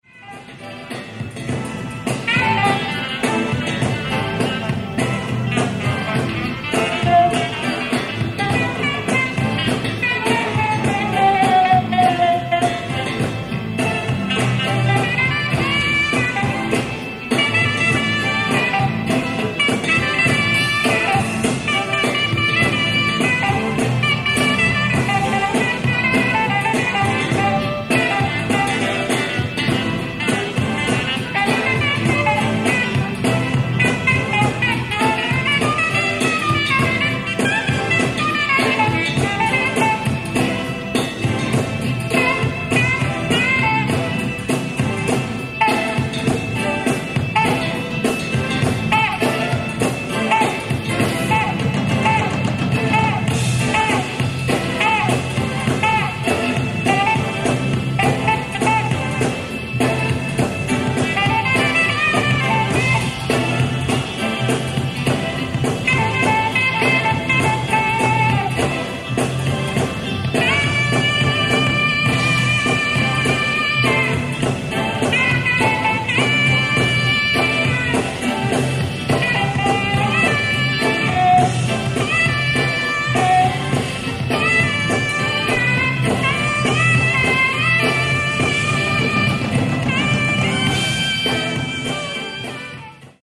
ライブ・アット・パレ・デ・スポール、パリ、フランス 06/30/1987
※試聴用に実際より音質を落としています。